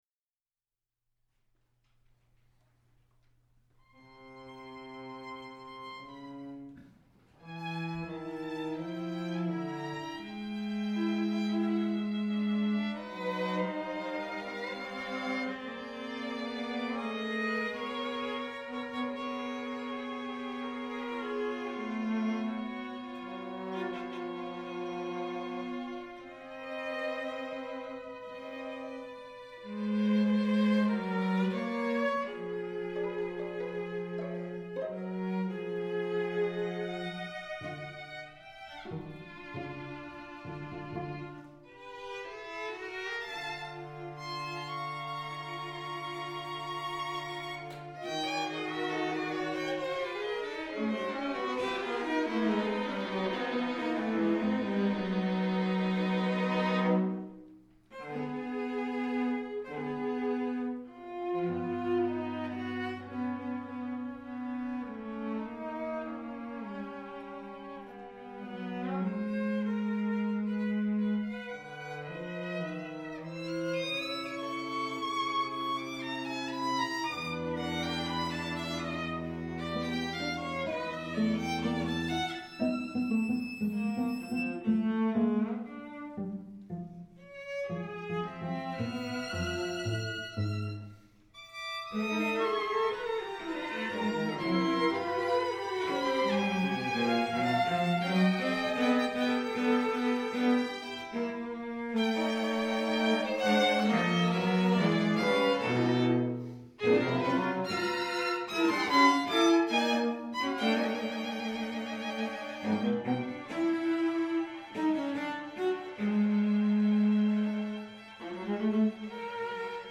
Portland Community Music Center
Experimentality (2010) Nova String Quartet
textures and colors.
the sections are set off by short pauses (silence).